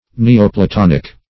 Search Result for " neoplatonic" : The Collaborative International Dictionary of English v.0.48: Neoplatonic \Ne`o*pla"ton"ic\, a. Of, pertaining to, or resembling, Neoplatonism or the Neoplatonists.